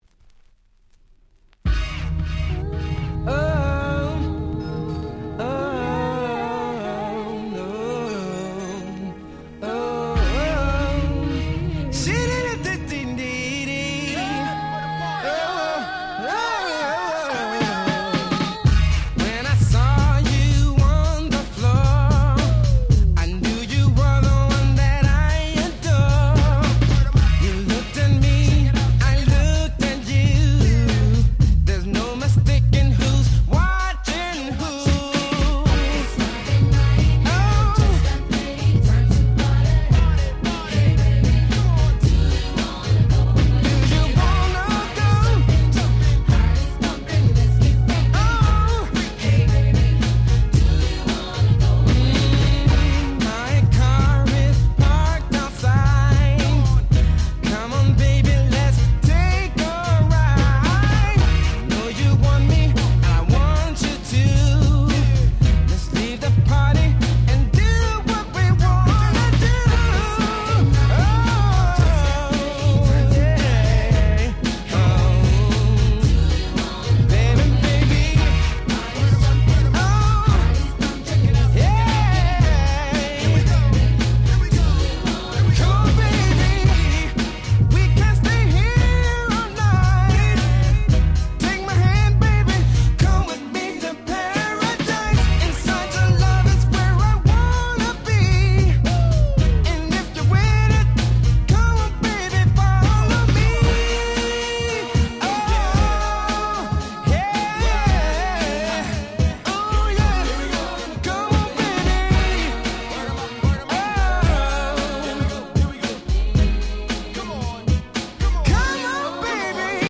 94' Very Nice R&B/New Jack Swing !!
お蔵入りには勿体無さ過ぎるソリッドなBeatに粋な歌声のハネる超絶ダンスナンバー！